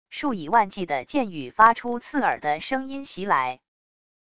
数以万计的箭雨发出刺耳的声音袭来wav音频